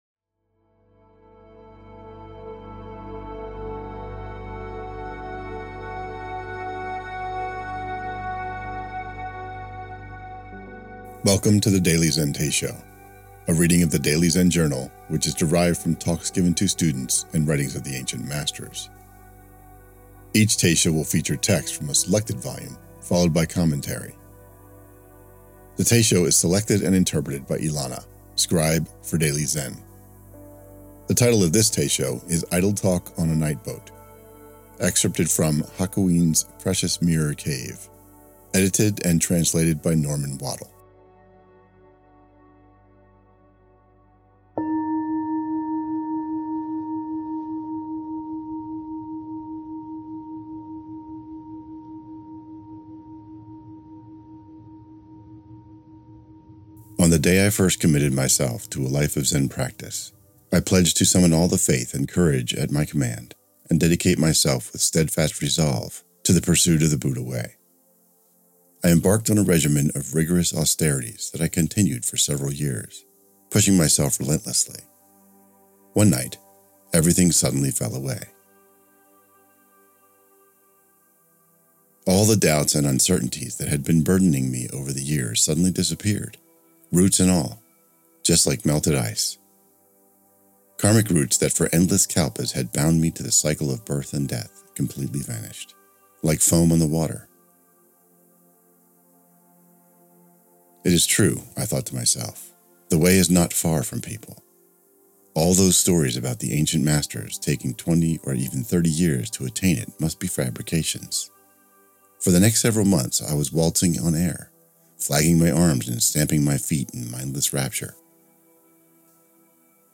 The Daily Zen Teisho Idle Talk on a Night Boat Play Episode Pause Episode Mute/Unmute Episode Rewind 10 Seconds 1x Fast Forward 30 seconds 00:00 / 20:06 Download file | Play in new window | Duration: 20:06